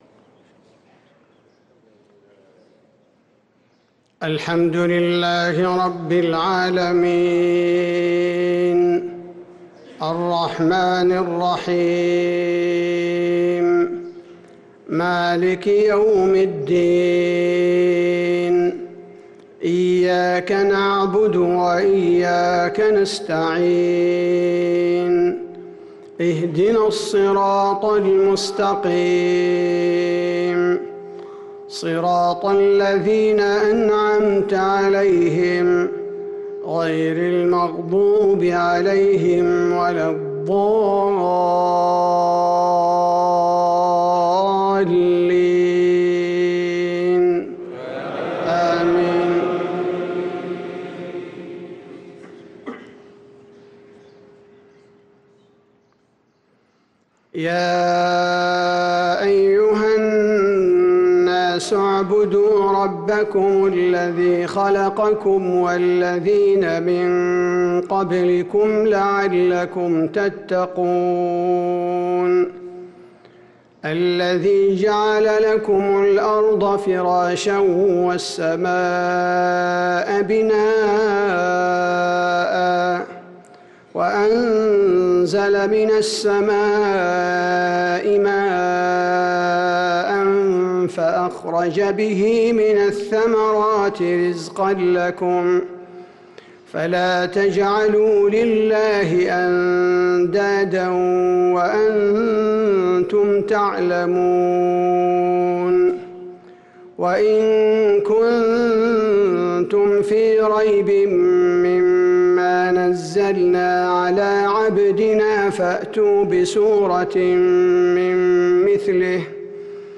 صلاة المغرب للقارئ عبدالباري الثبيتي 18 رجب 1445 هـ
تِلَاوَات الْحَرَمَيْن .